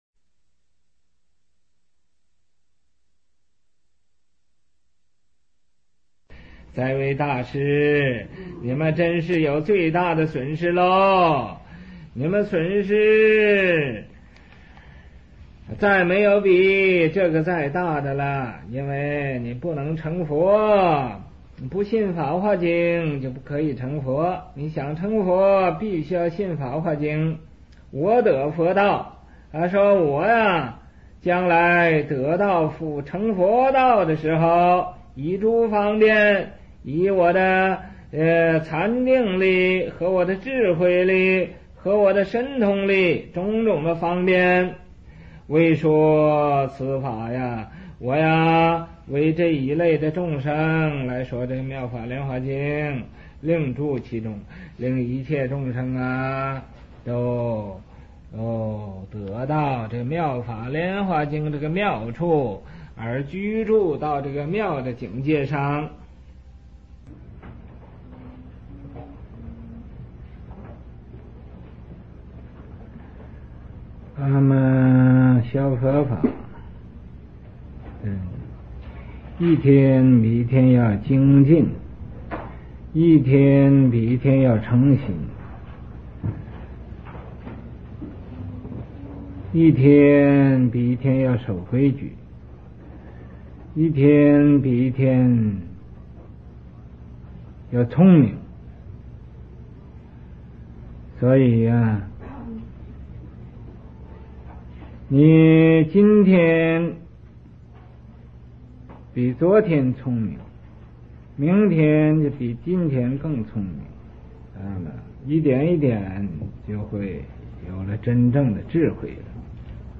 佛學講座